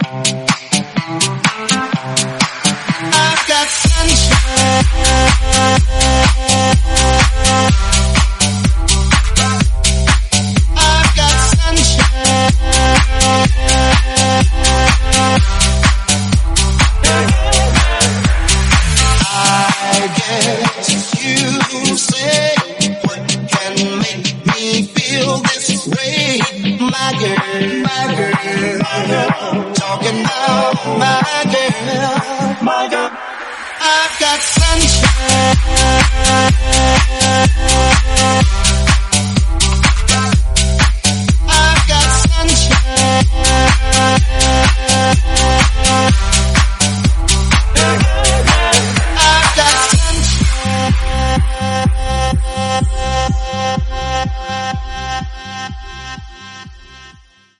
Genre: EDM
Clean BPM: 125 Time